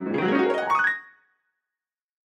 Звуки пианино
Звук провели рукой по клавишам пианино